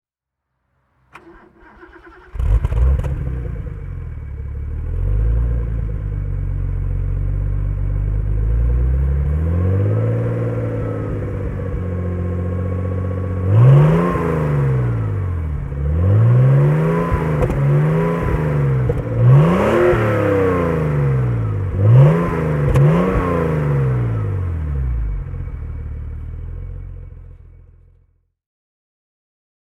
Motorsounds und Tonaufnahmen zu Alpina B7 Turbo Fahrzeugen (zufällige Auswahl)
BMW-Alpina B7 Turbo Coupé (1987) - Starten und Leerlauf